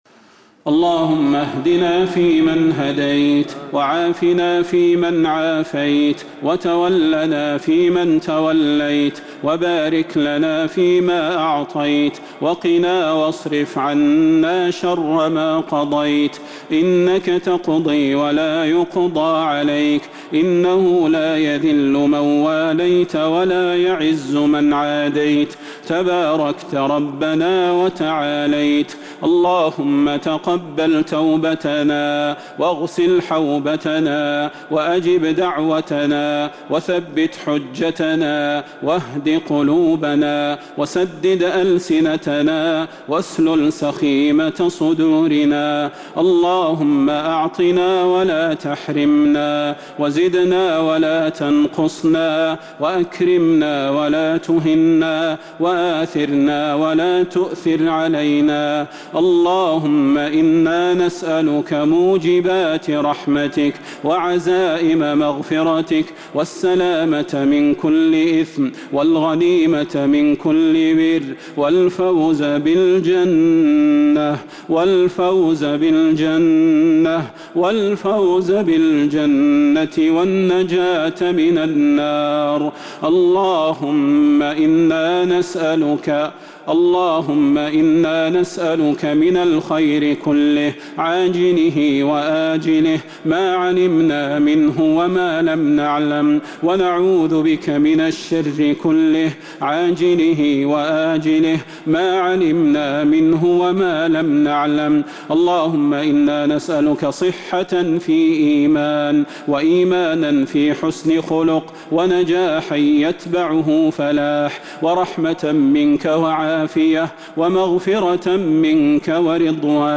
دعاء القنوت ليلة 9 رمضان 1442هـ | Dua for the night of 9 Ramadan 1442H > تراويح الحرم النبوي عام 1442 🕌 > التراويح - تلاوات الحرمين